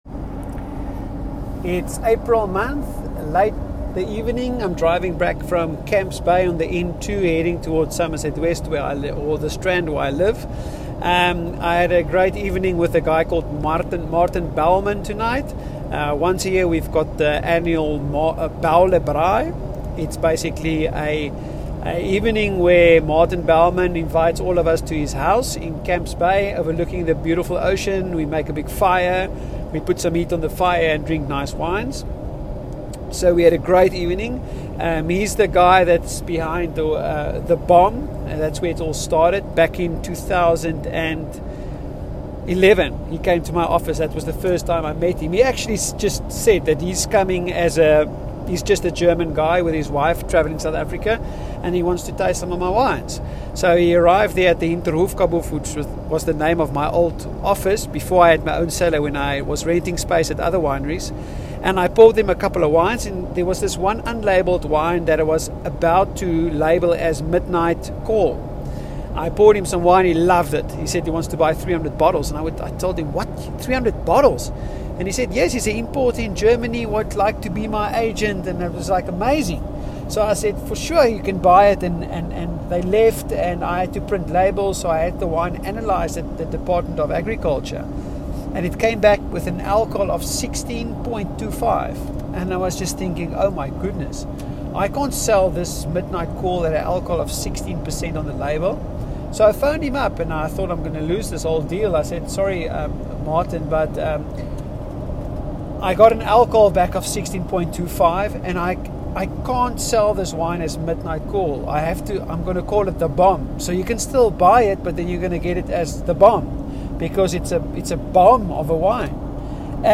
Voice Recording: